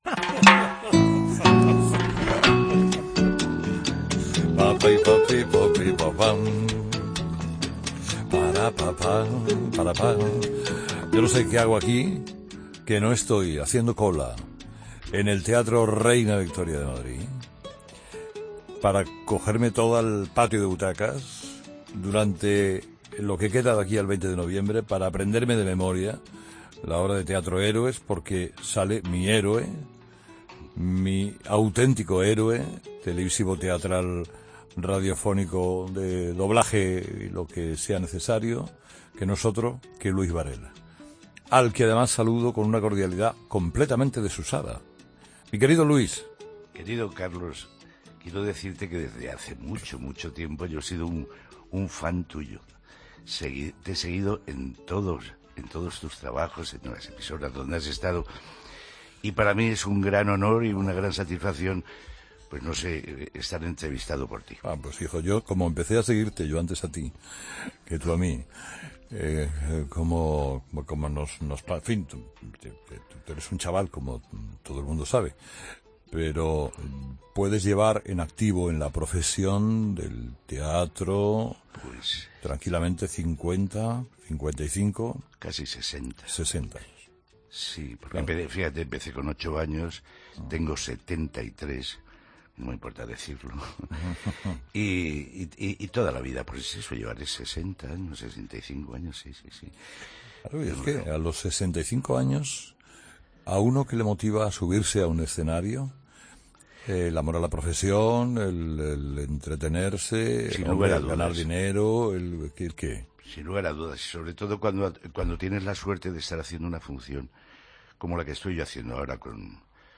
Escucha la entrevista al actor Luis Varela en 'Herrera en COPE'